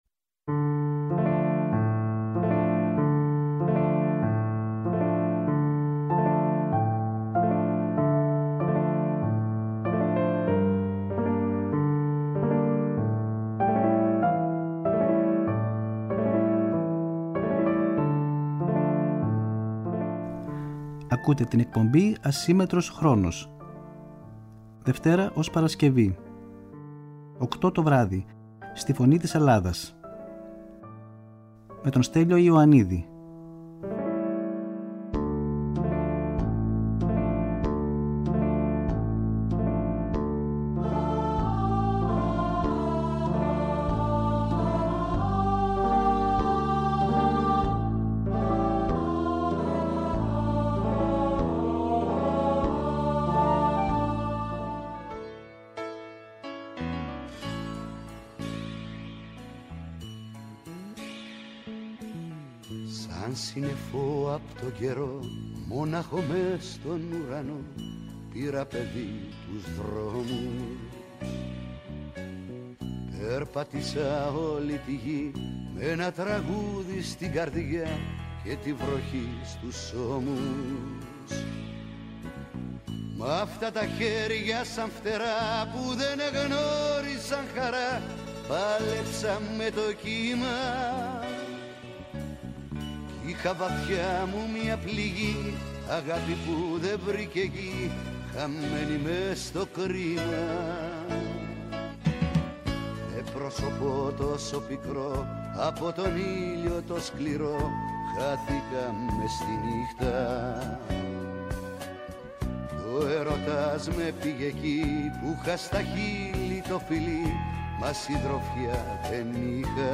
Μια εκπομπή με τραγούδια που αγαπήσαμε, μελωδίες που ξυπνούν μνήμες, αφιερώματα σε σημαντικούς δημιουργούς, κυρίως της ελληνικής μουσικής σκηνής, ενώ δεν απουσιάζουν οι εκφραστές της jazz και του παγκόσμιου μουσικού πολιτισμού.